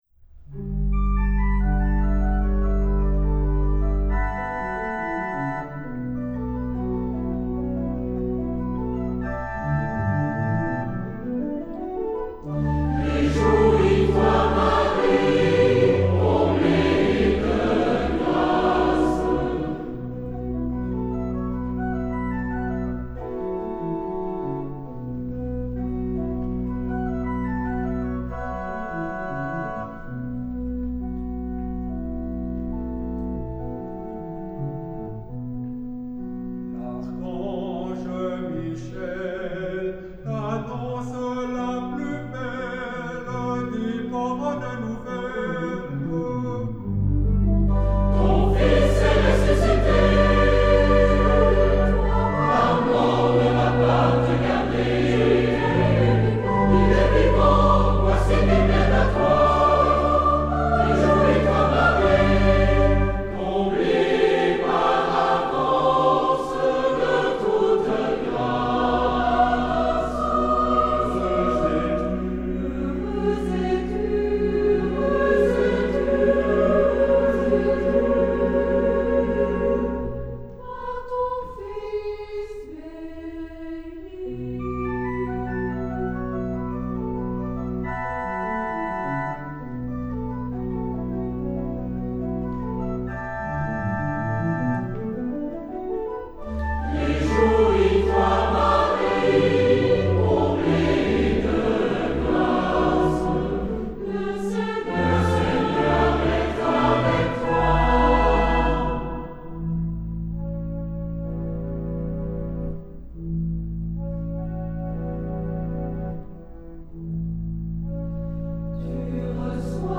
SATB (4 voices mixed) ; Full score.
Sacred. Prayer. Choir.
Mood of the piece: various ; meditative ; prayerful
Instruments: Organ (1)
Tonality: tonal ; various